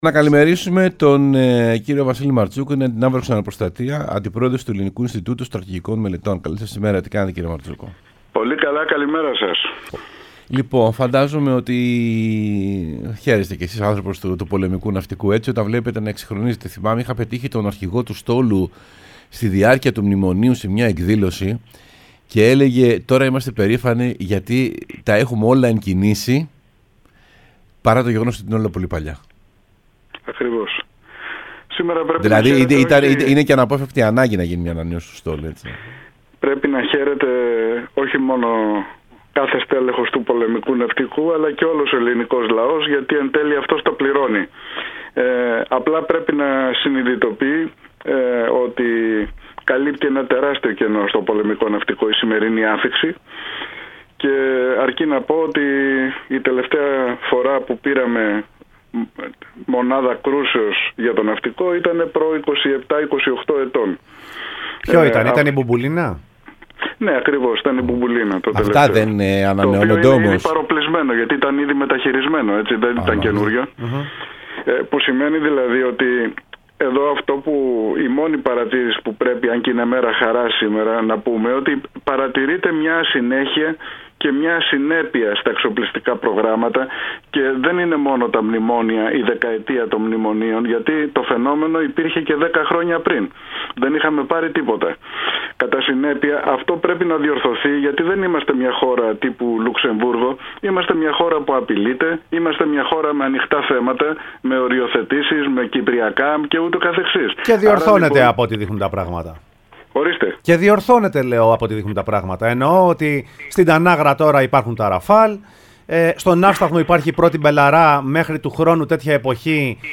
μίλησε στην εκπομπή Σεμνά και ταπεινά